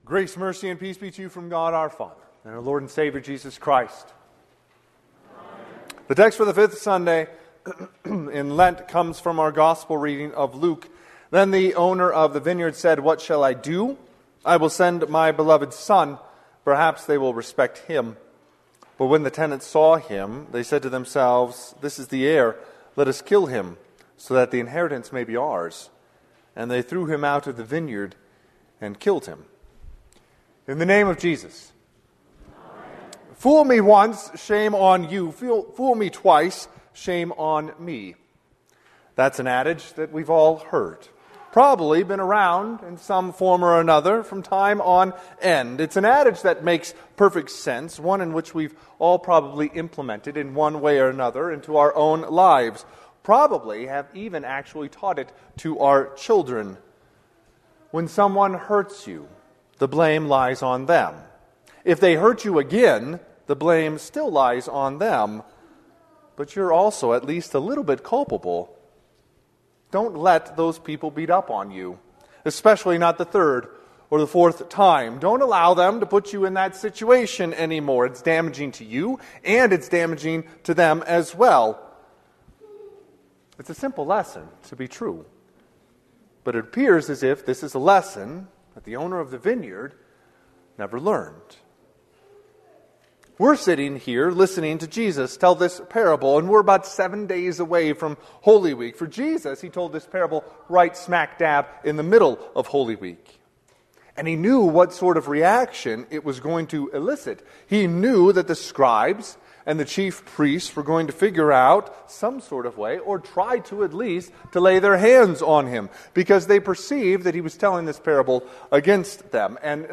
Sermon - 4/6/2025 - Wheat Ridge Lutheran Church, Wheat Ridge, Colorado
Fifth Sunday in Lent